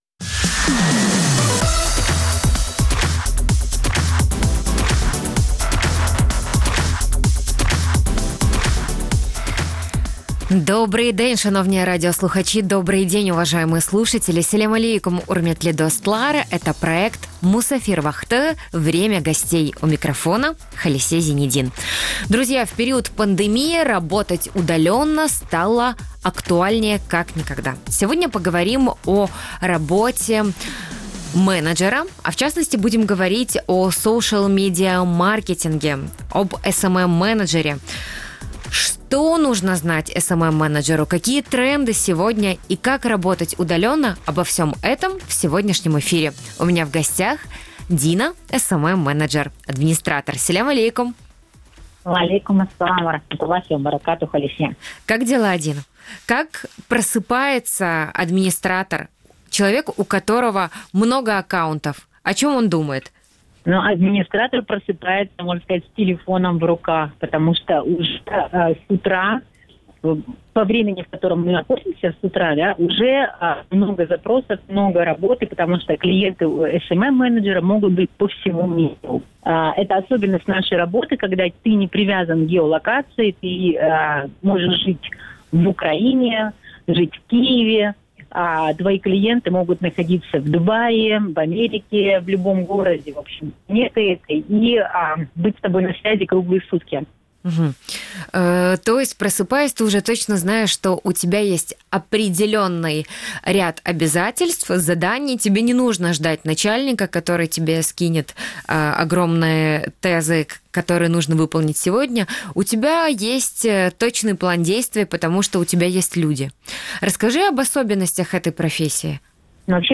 Гость программы SMM-менеджер